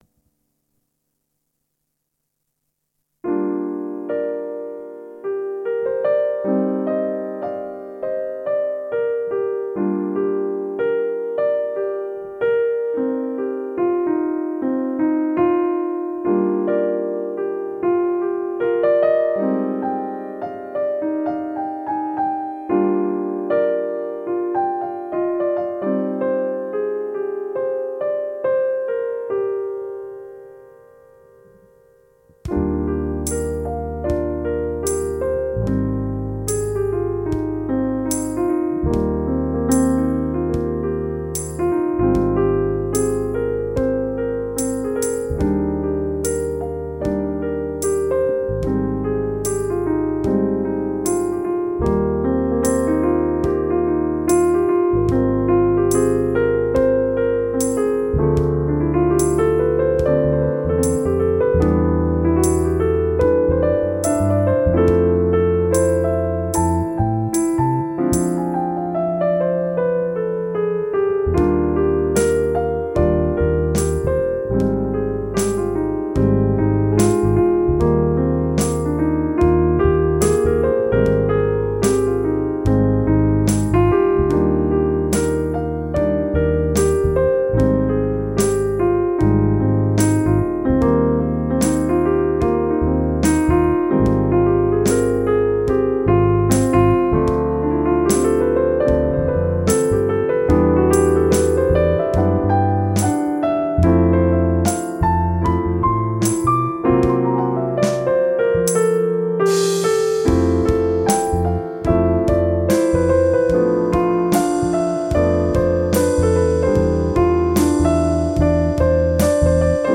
Piano,Bass & Drum Jazz Ballad